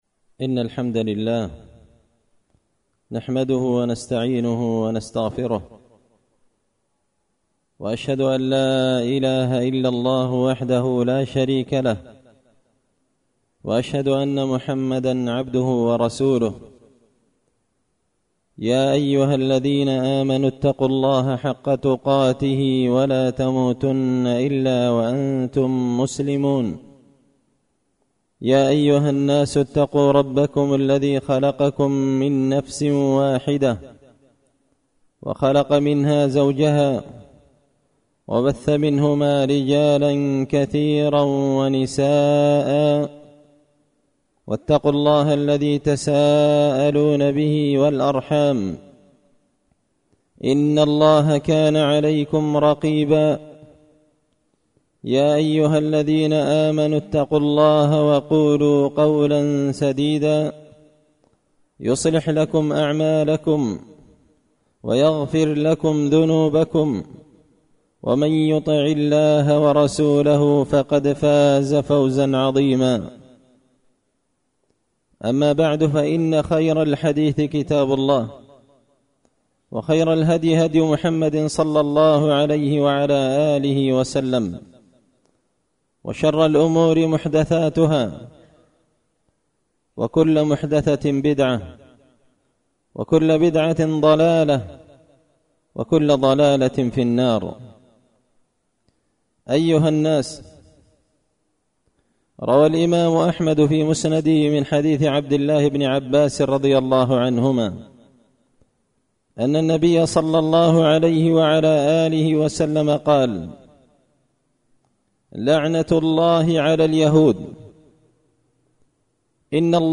خطبة جمعة بعنوان – مخالفات أخرى في البيوع والمعاملات
دار الحديث بمسجد الفرقان ـ قشن ـ المهرة ـ اليمن